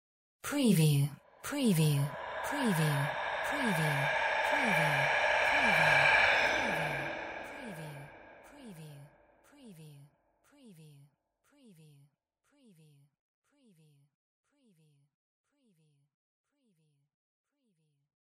Surreal whisper rev 019
Stereo sound effect - Wav.16 bit/44.1 KHz and Mp3 128 Kbps
previewSCIFI_WHISPERS_SPOOKY_REV_WBHD019.mp3